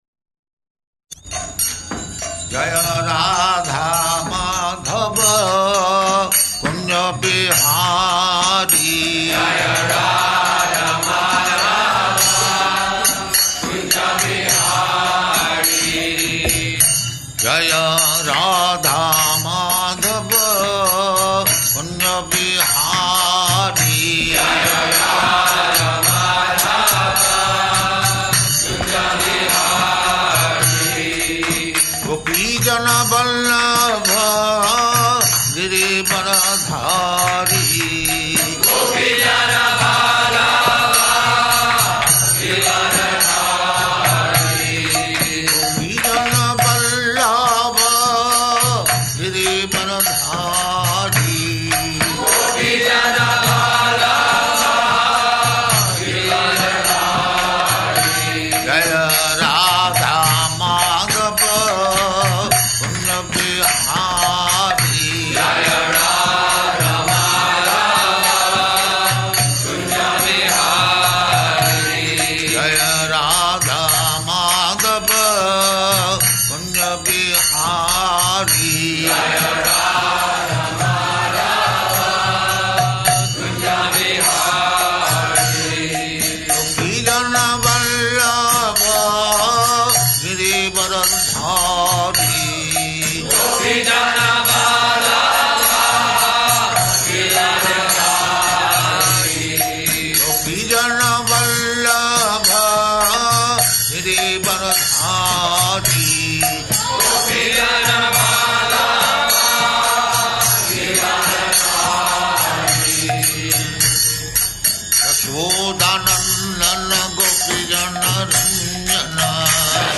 Location: Los Angeles
[leads singing of Jaya Rādhā-Mādhava ]